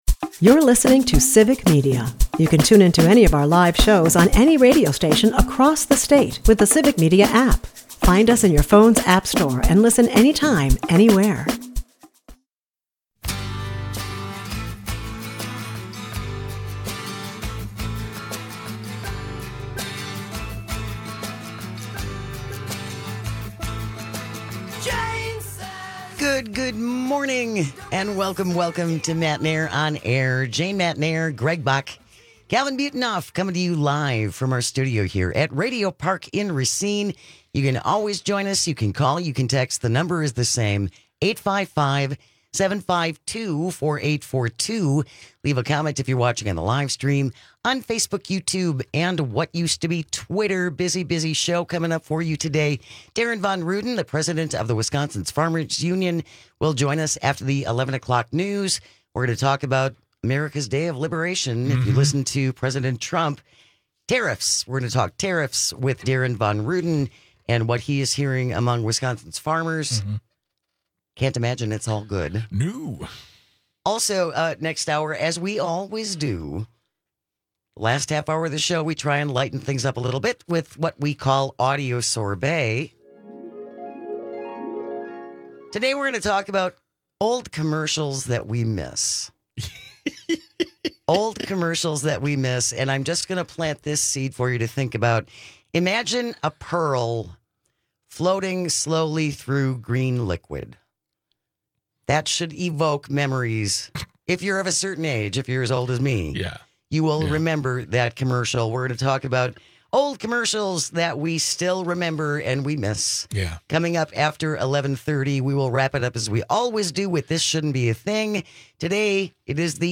In the meantime, we hear from our listeners about how they feel, and what hope they have for the future of the state.